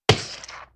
splat2.ogg